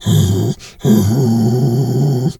bear_roar_soft_08.wav